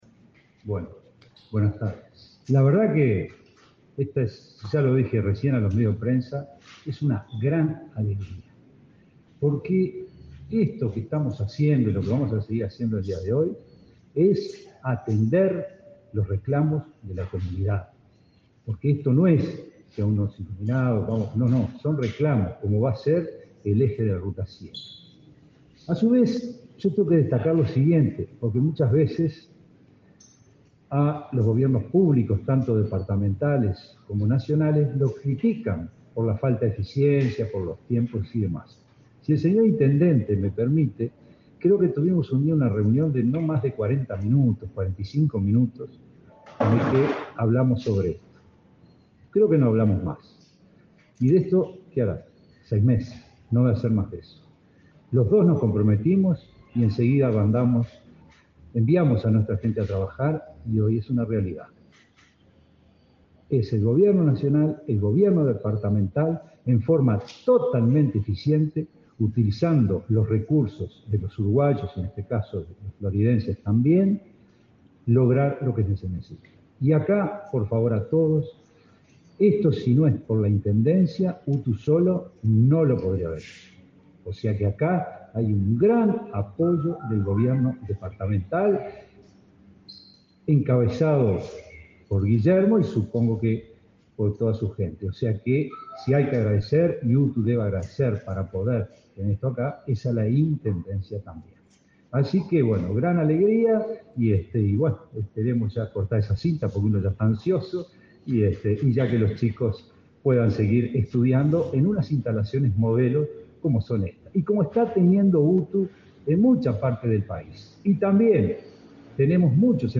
Palabras del director general de Educación Técnico Profesional, Juan Pereyra
Autoridades de UTU inauguraron, este 17 de marzo, instalaciones educativas en Florida, Cerro Colorado y Casupá, todas parte del proyecto Eje Ruta 7